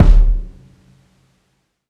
live_kick_sub.wav